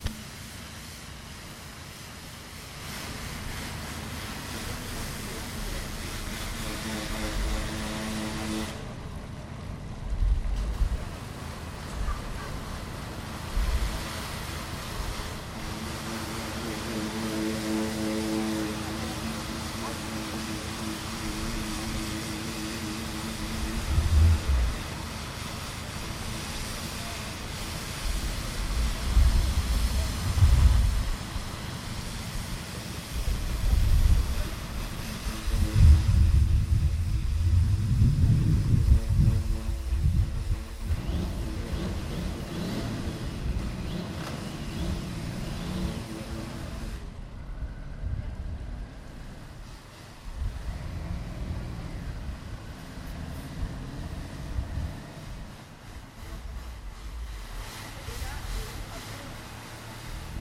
Karcher, moto, écoulement de l'eau (fontaine)